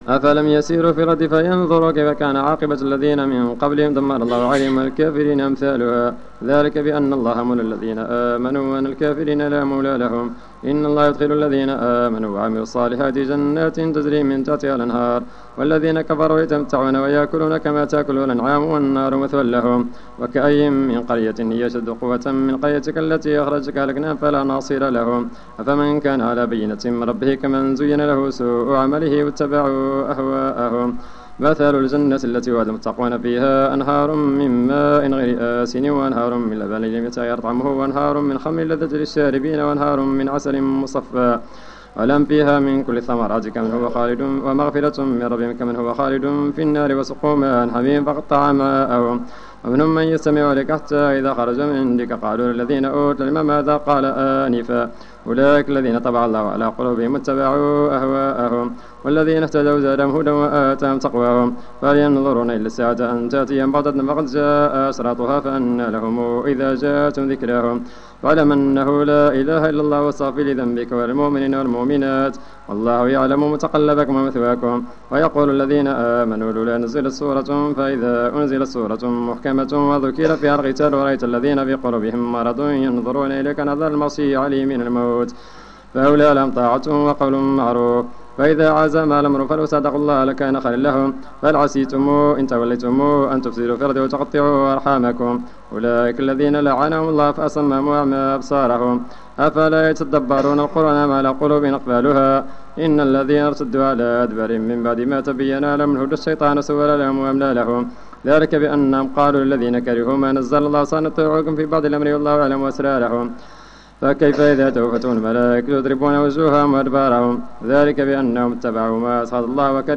صلاة التراويح ليوم 25 رمضان 1431 بمسجد ابي بكر الصديق ف الزو
صلاة رقم 02 ليوم 25 رمضان 1431 الموافق سبتمبر 2010